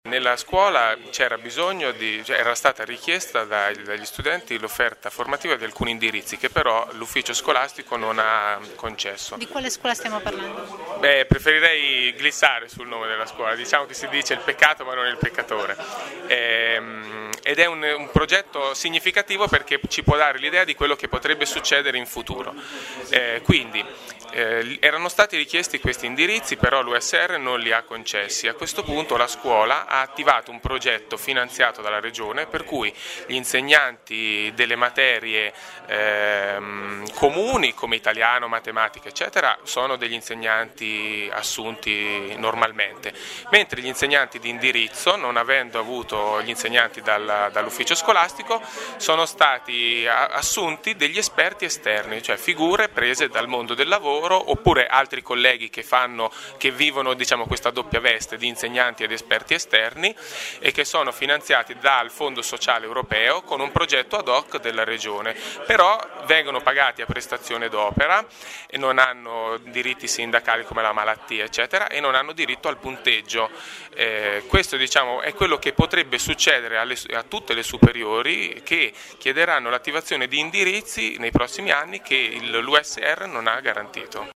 Uno di loro, che preferisce rimanere anonimo, spiega che la riforma prevede anche l’introduzione nelle scuole della figura degli esperti esterni, fino al 40% del corpo docente.